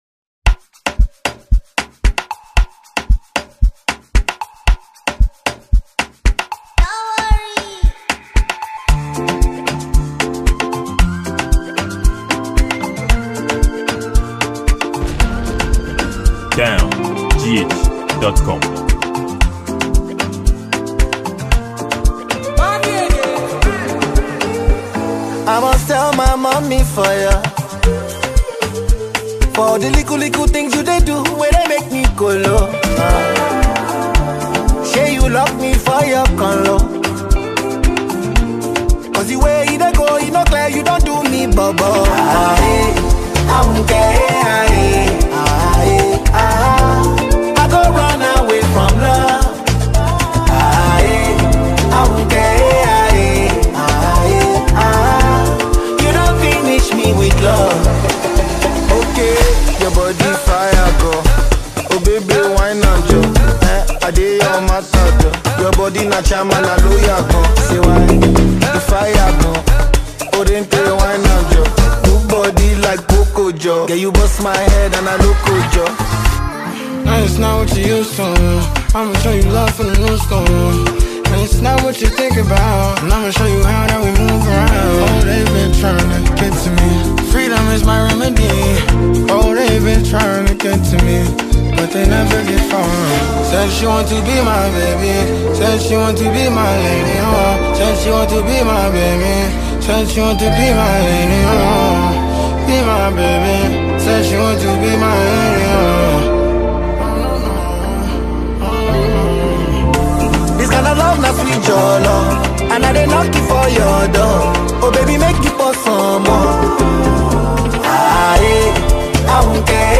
a captivating afrobeat song